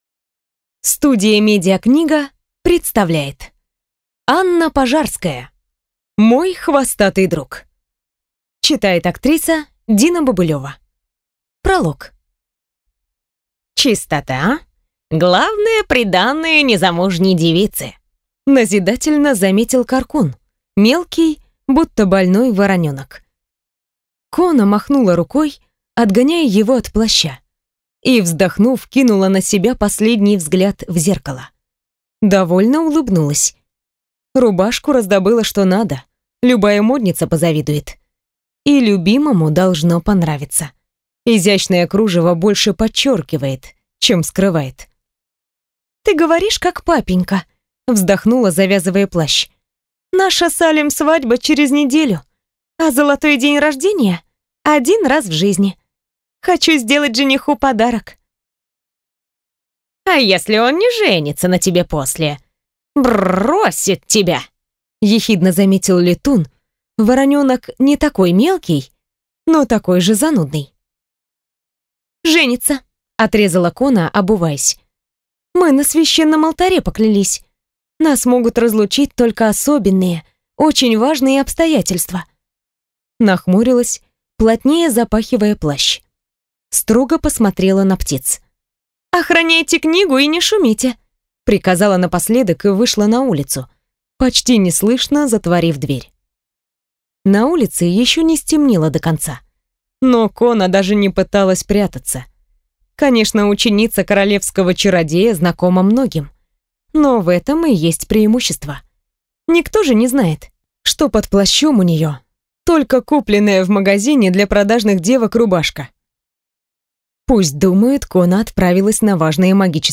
Аудиокнига Мой хвостатый друг | Библиотека аудиокниг